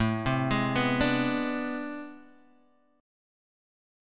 AmM11 chord